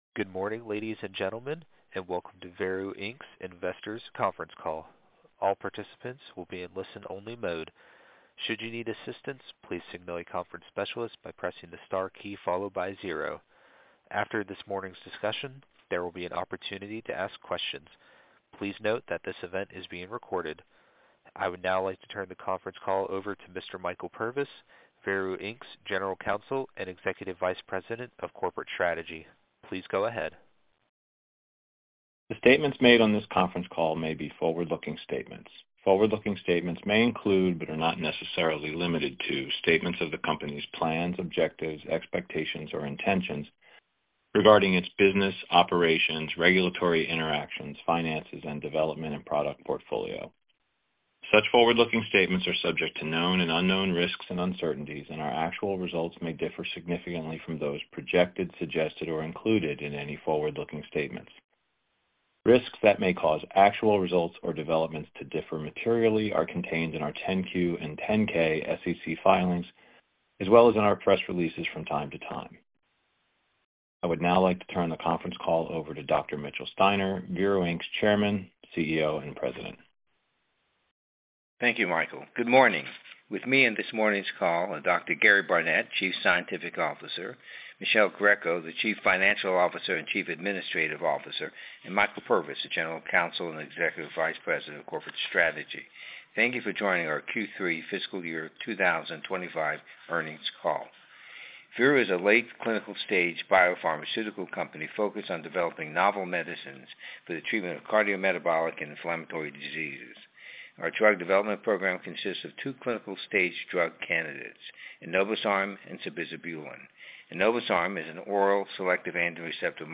FY 2025 Q3 Earnings Conference Call